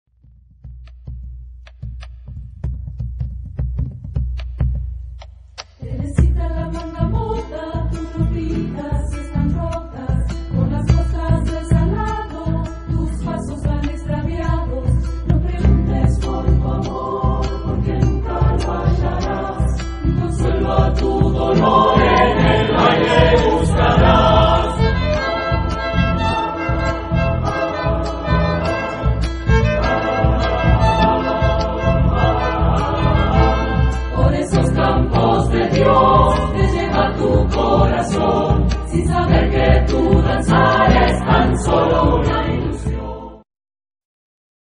Type of Choir: SATBarB  (5 mixed voices )
Tonality: C major